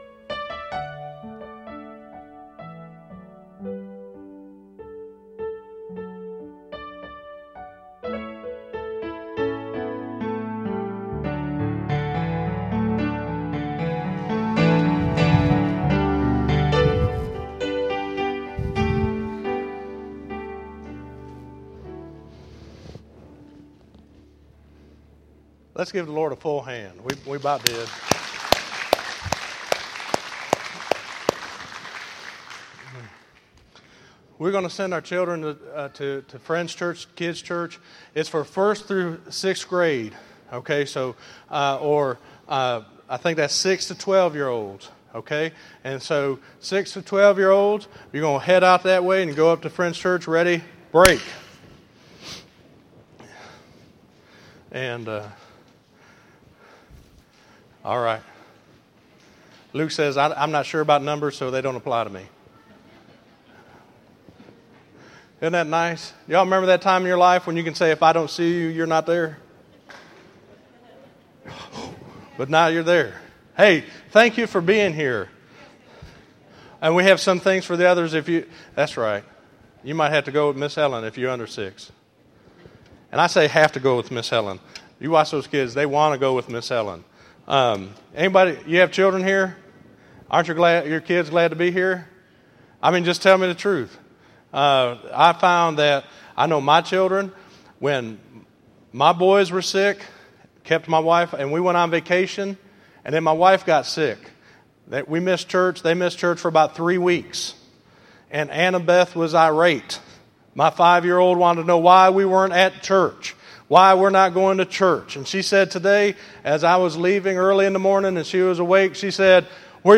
9_1_13_Sermon.mp3